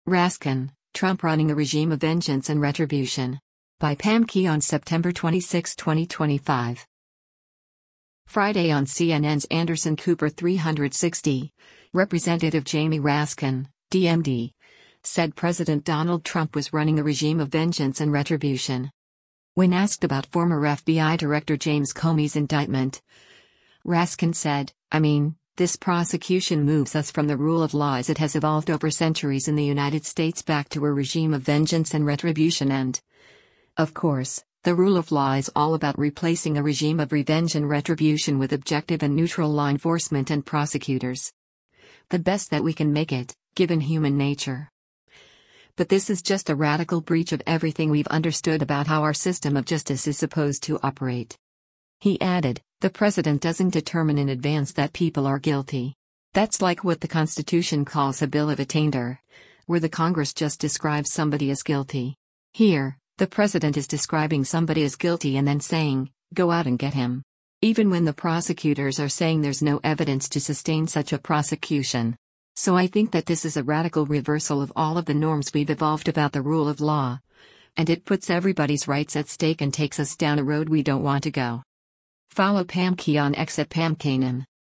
Friday on CNN’s “Anderson Cooper 360,” Rep. Jamie Raskin (D-MD) said President Donald Trump was running a “regime of vengeance and retribution.”